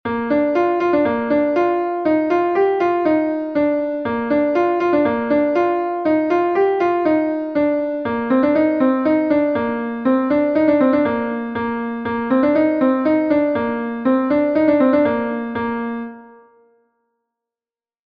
Gavotenn Ploure II is a Gavotte from Brittany